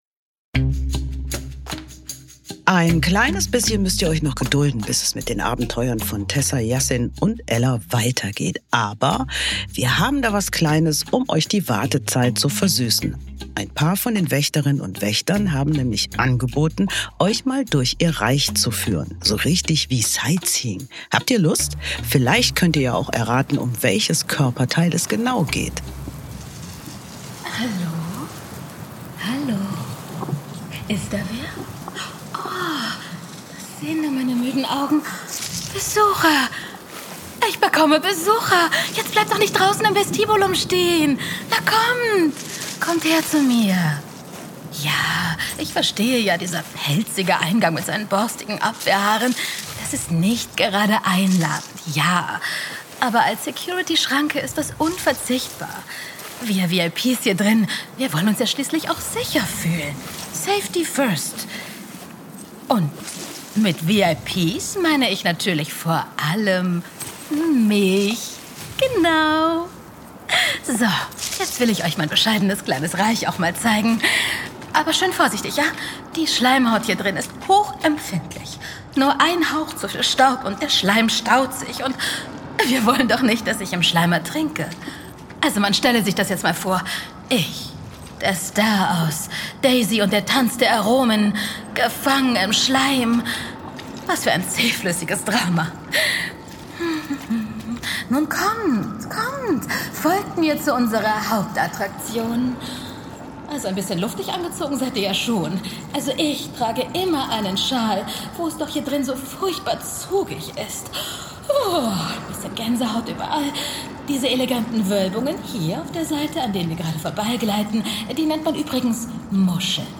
Taxi ins Mich | Der Hörspiel-Podcast für Kinder